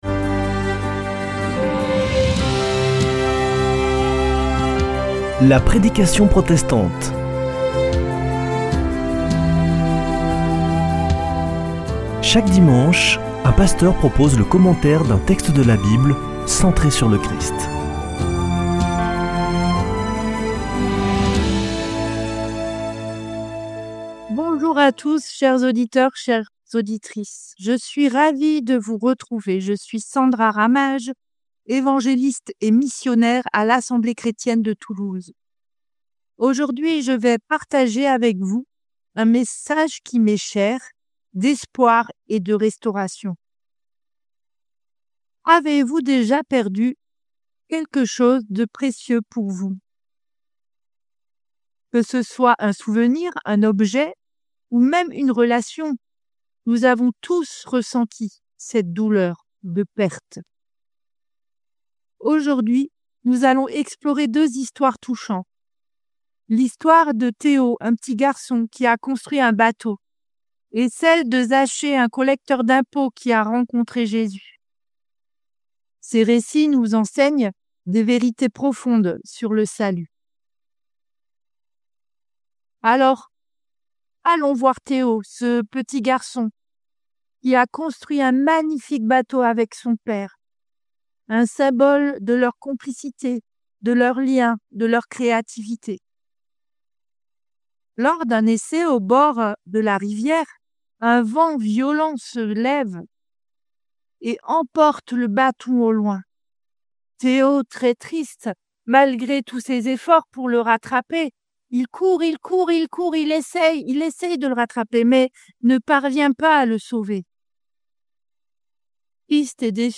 Accueil \ Emissions \ Foi \ Formation \ La prédication protestante \ Un message d’Espoir : restauration et transformation spirituelle.